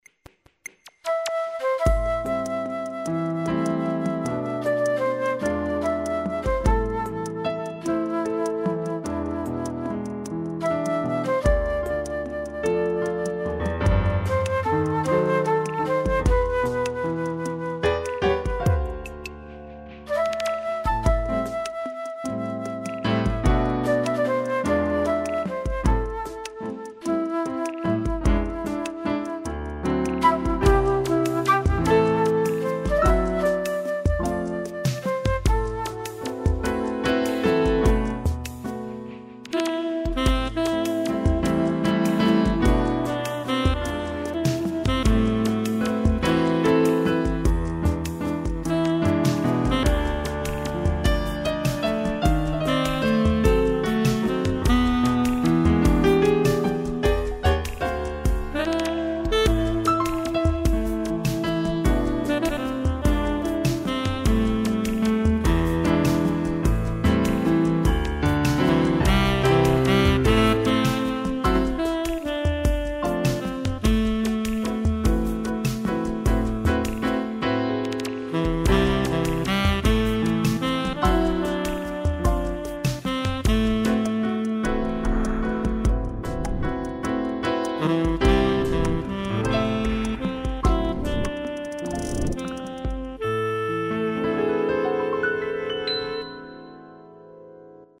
CD Instrumental